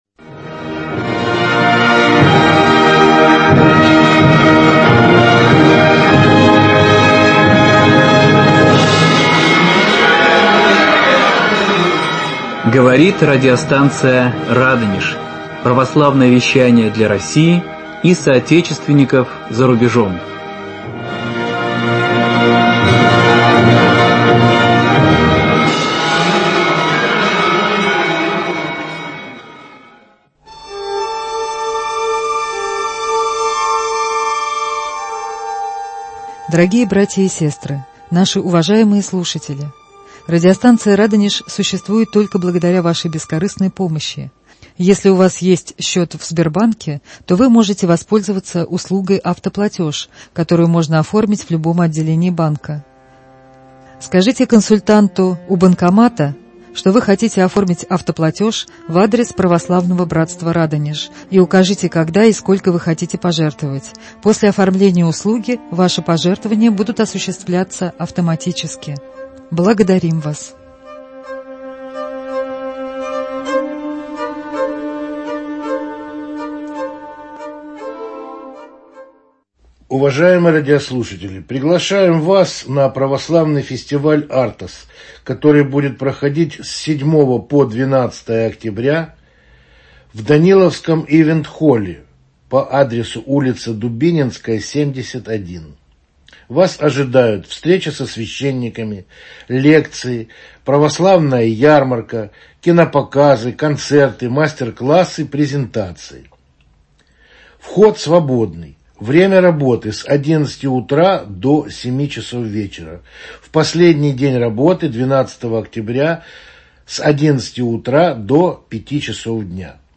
В студии радиостанции "Радонеж"